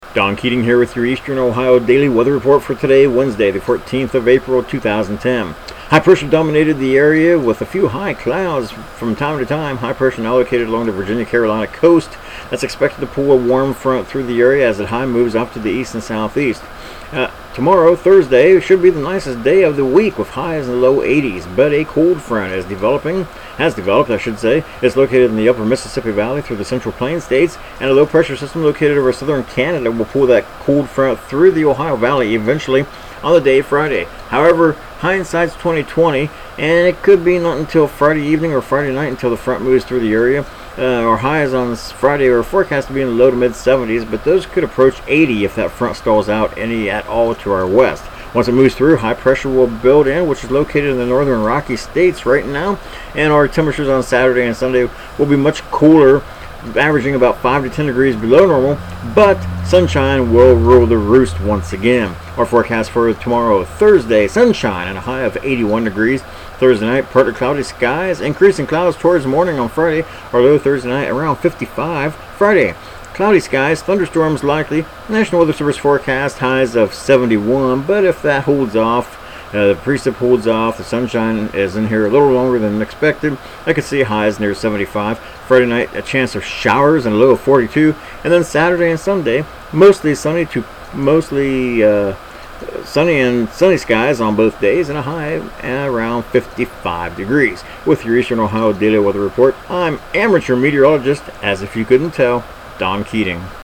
Tags: Weather Ohio Newcomerstown Forecast Report